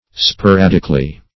Sporadically \Spo*rad"ic*al*ly\, adv.